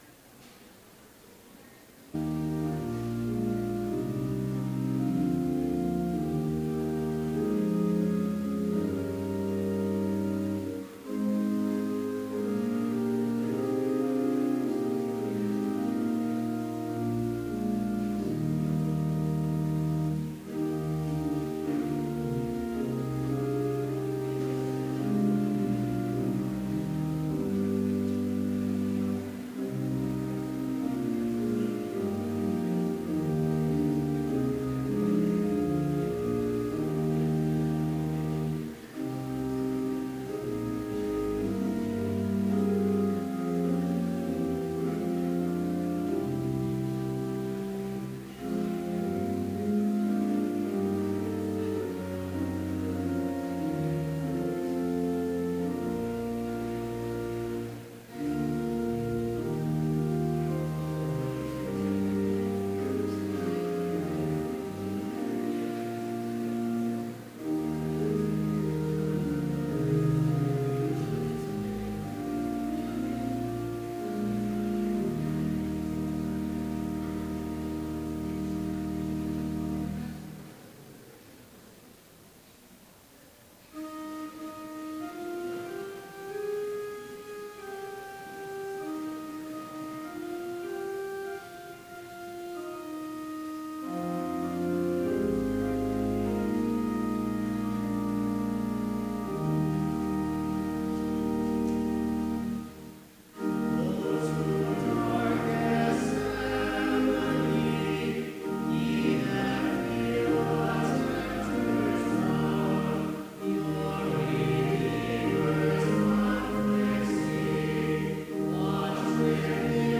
Complete service audio for Chapel - March 3, 2016